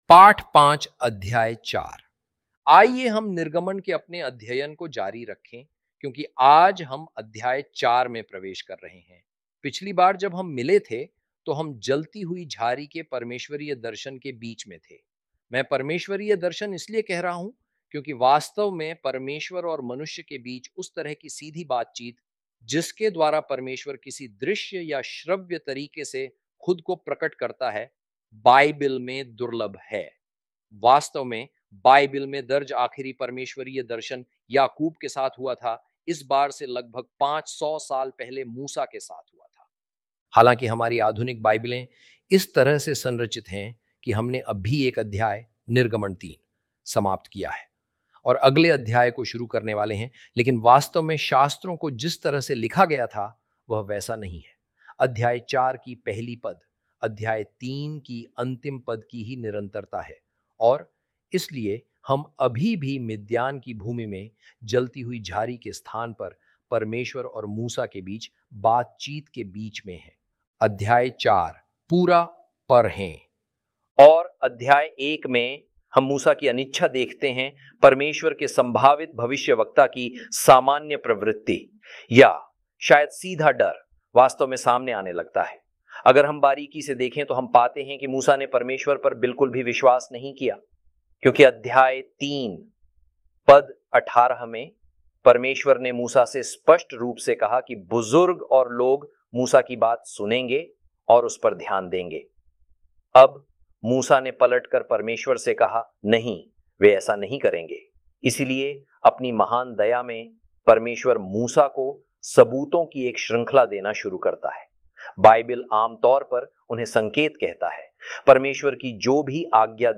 hi-audio-exodus-lesson-5-ch4.mp3